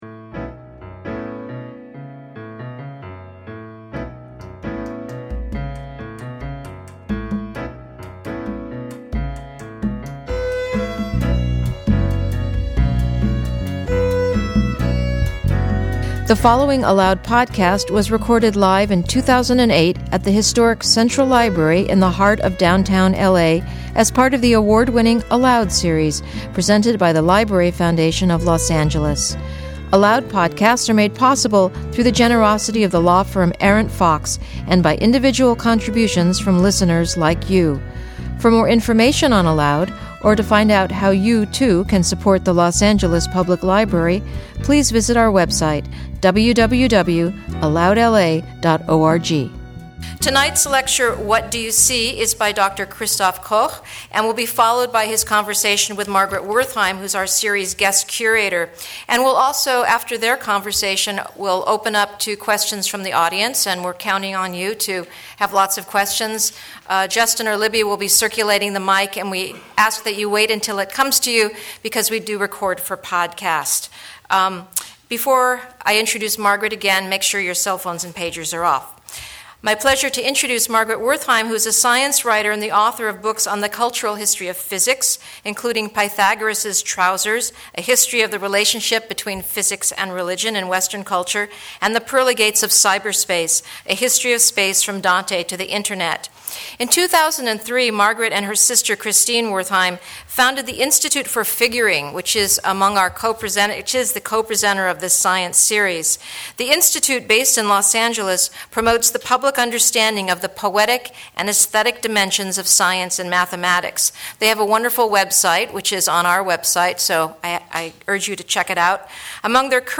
email In conversation with Margaret Wertheim, Institute for Figuring Wednesday, May 21, 2008 01:15:51 ALOUD Listen: play pause stop / 90.mp3 Listen Download this episode Episode Summary How do our brains construct a world from a confounding and often conflicting mass of visual cues?